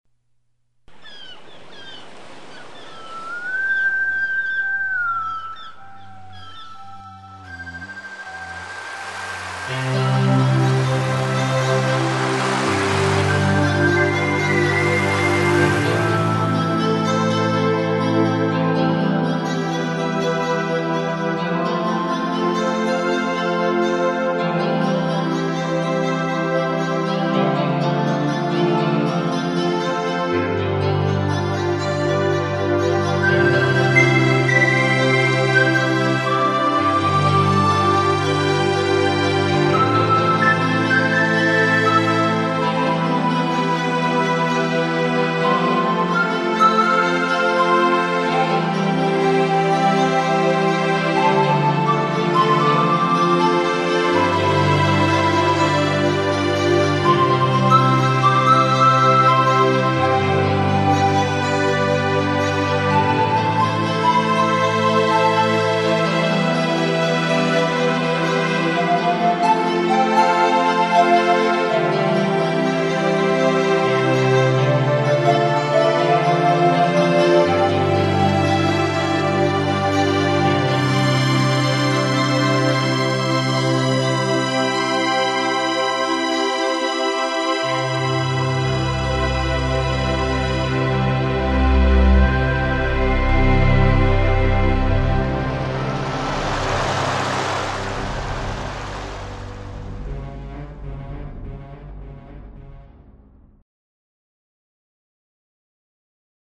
19- Billy Bud Overture – November 1996 - an adapted and improved version of my overture for "For our Country's Good" at Theatre Macon [May 1993] Uses a traditional motif from the Boswain's whistle and the childhood icon tune "Sailing, Sailing".
Sounds - Roland Sound Canvass sequenced with Cakewalk Pro, version 3